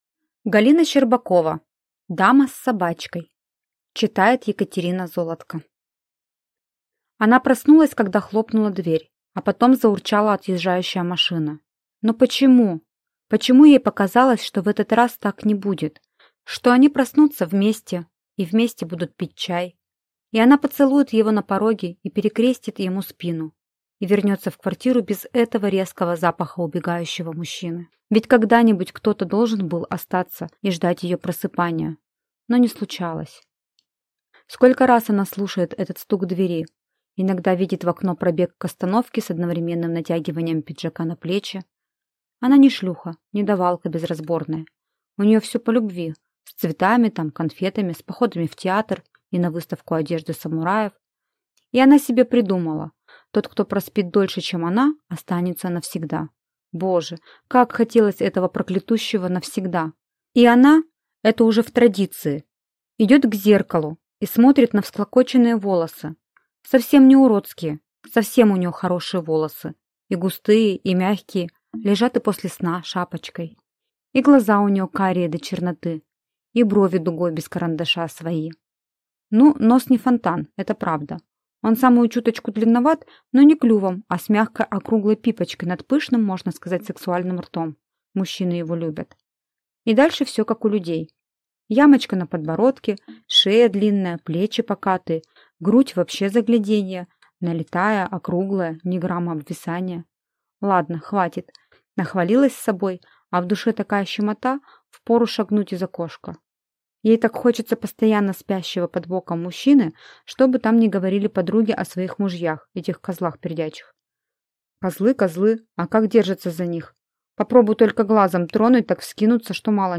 Аудиокнига Дама с собачкой | Библиотека аудиокниг